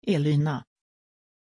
Aussprache von Élyna
pronunciation-élyna-sv.mp3